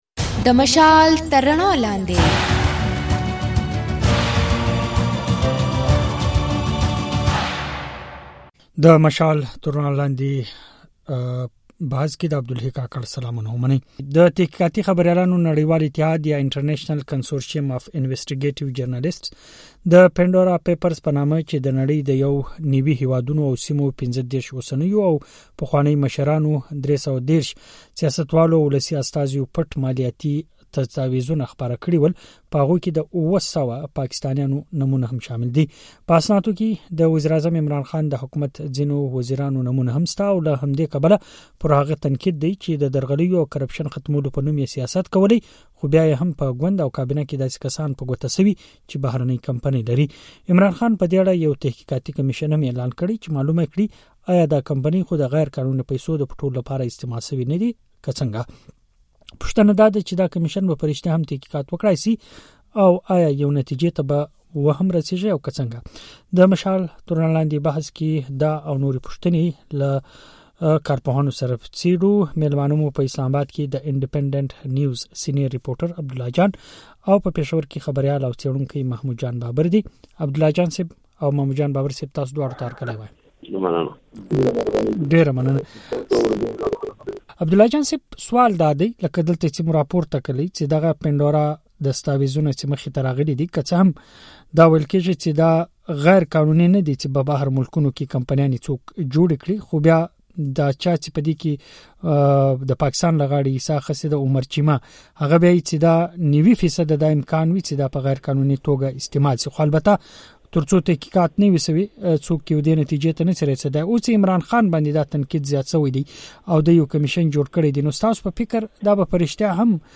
دا د مشال راډیو د ځانګړي بحث او شننو اوونیزې خپرونې پاڼه ده.